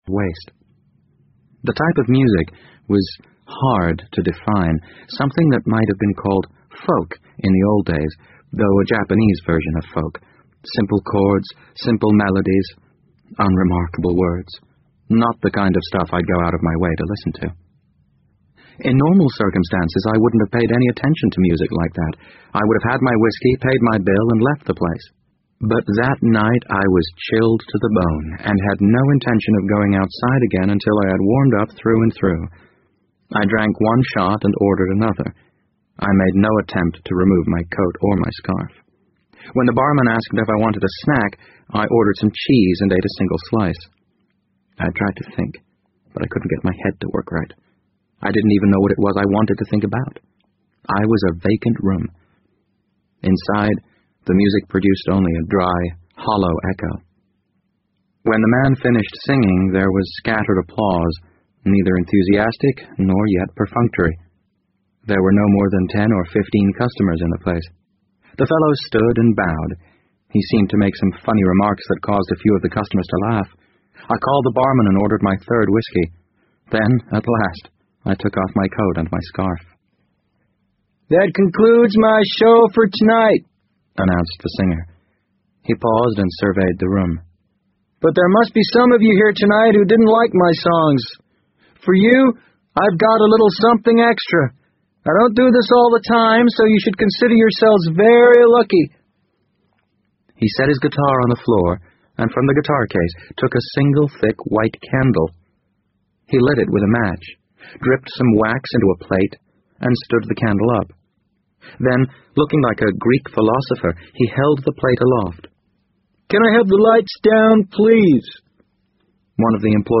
BBC英文广播剧在线听 The Wind Up Bird 006 - 18 听力文件下载—在线英语听力室